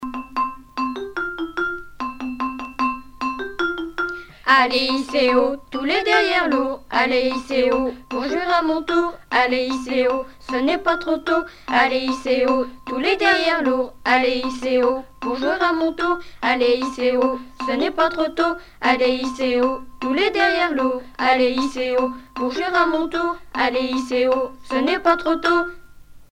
Genre brève
Pièce musicale éditée